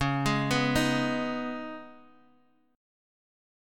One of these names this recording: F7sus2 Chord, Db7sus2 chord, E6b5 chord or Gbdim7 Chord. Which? Db7sus2 chord